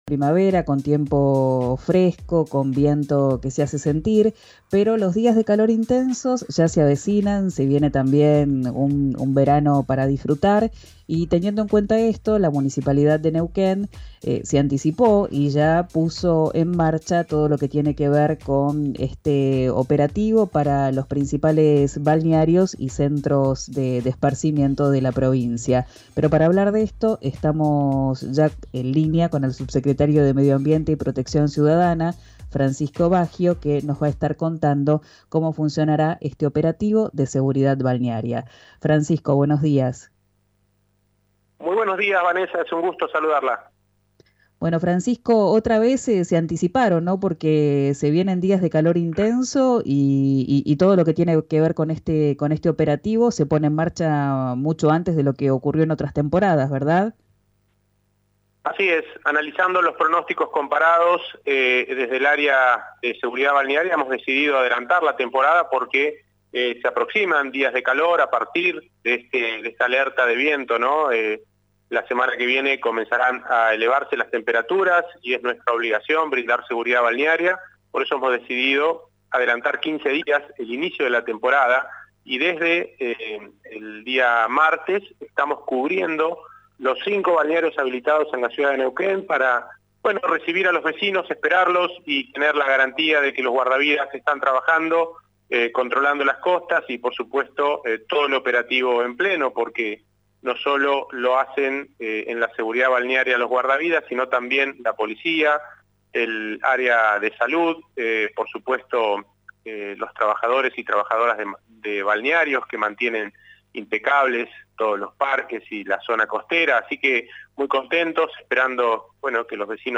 El subsecretario de Medio Ambiente y Protección Ciudadana del municipio, Francisco Baggio, habló con RIO NEGRO RADIO y dio un panorama de la ciudad, donde advirtió que ya se registró caída de árboles y carteles.